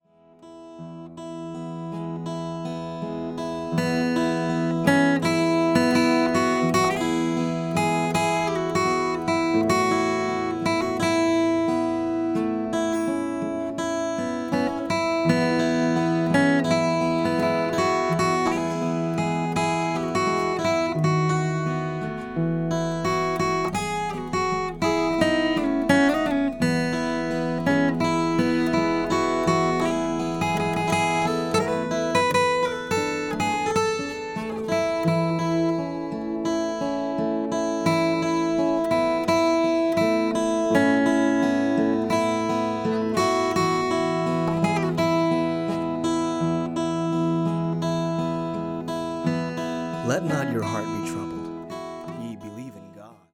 on guitar
on ukulele